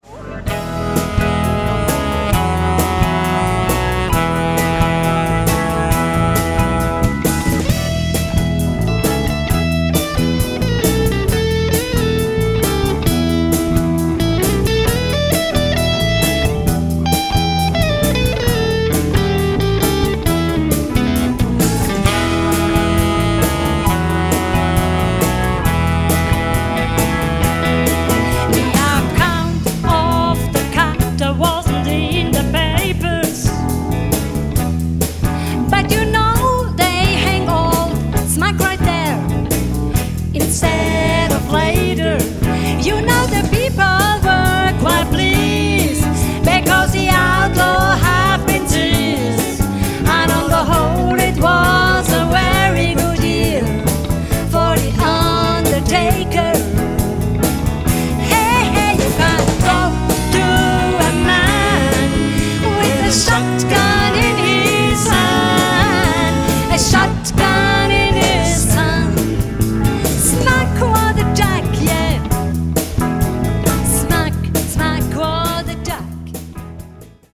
Jubiläumskonzert